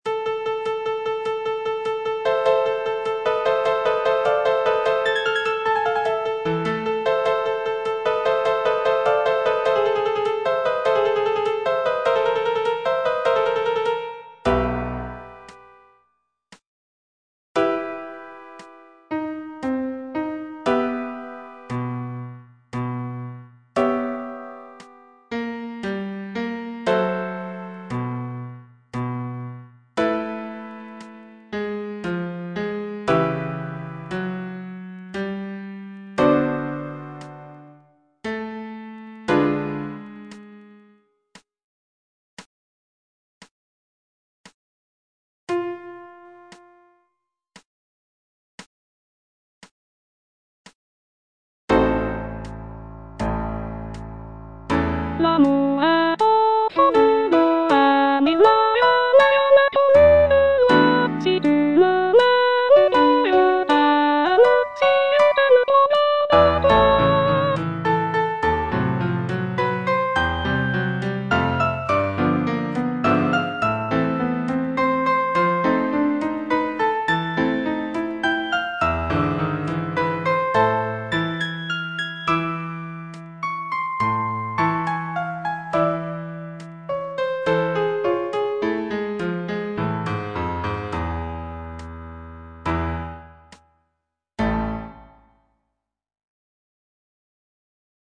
G. BIZET - CHOIRS FROM "CARMEN" Carmen, sur tes pas (soprano II) (Voice with metronome) Ads stop: auto-stop